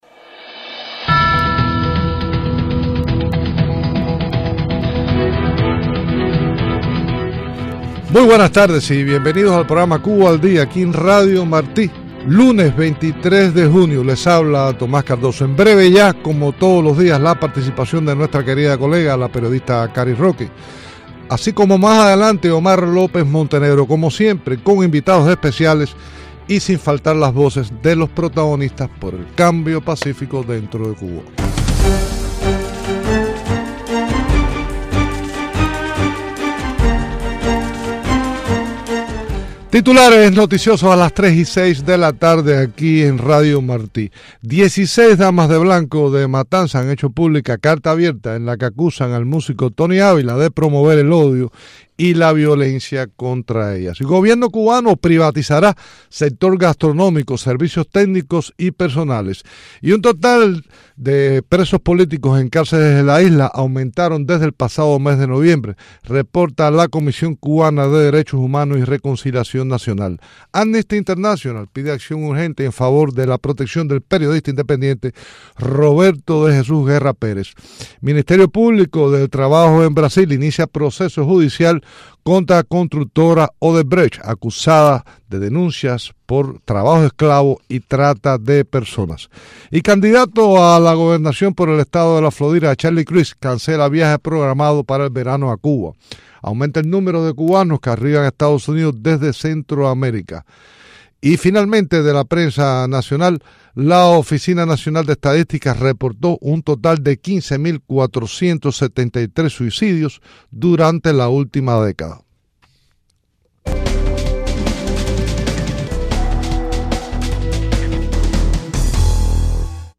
Entrevistas con Berta Soler